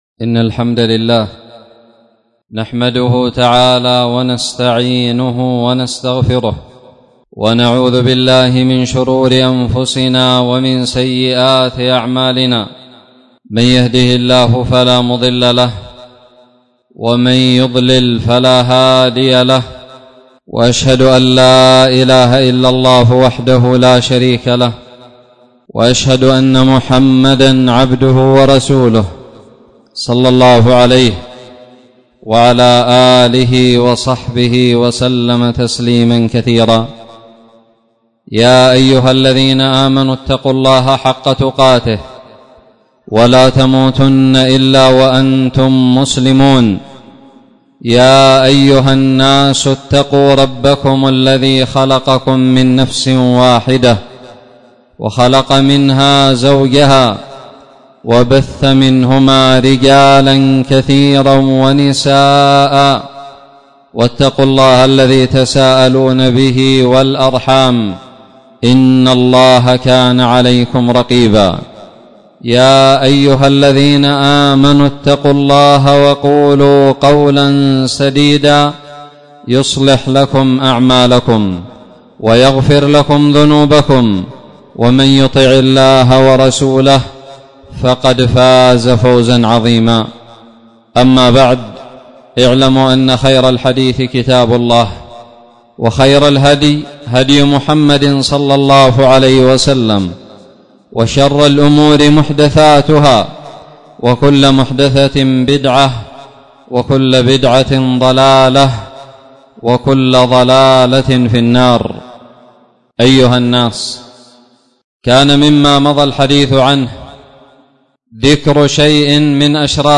خطب الجمعة
ألقيت بدار الحديث السلفية للعلوم الشرعية بالضالع في 3 شعبان 1441هــ